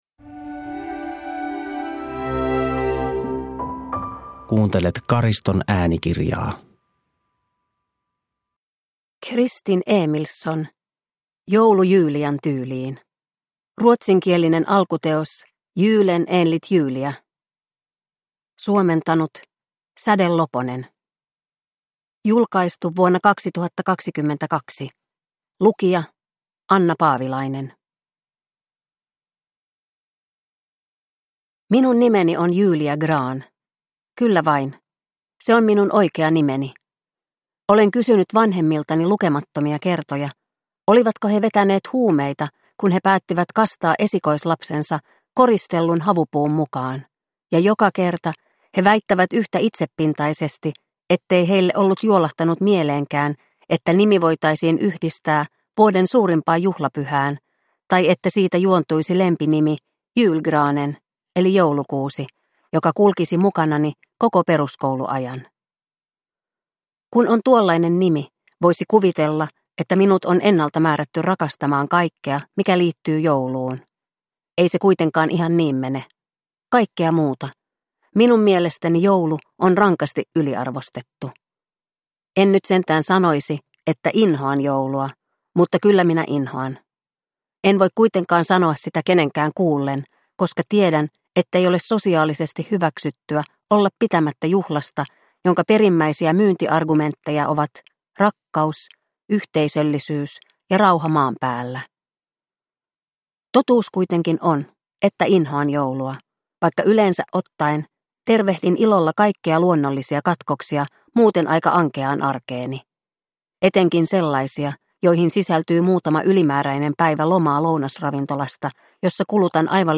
Joulu Julian tyyliin – Ljudbok – Laddas ner